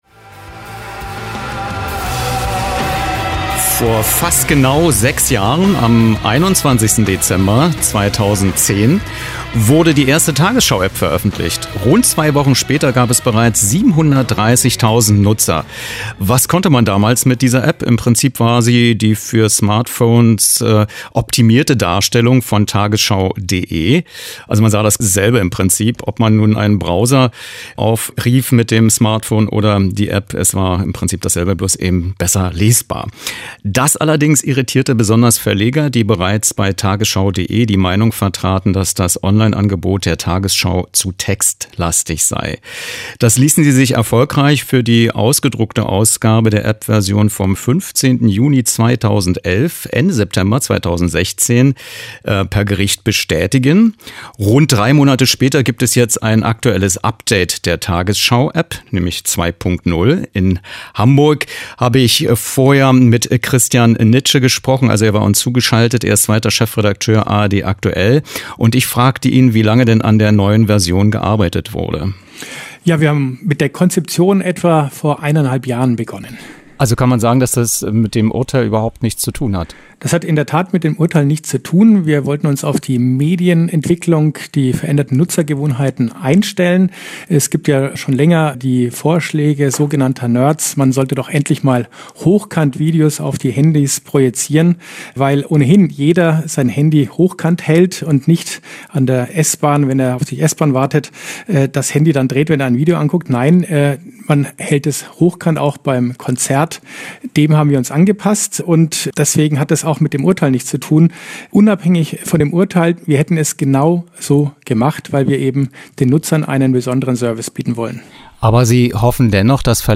Was: Interview zur tagesschau2.0-App Wer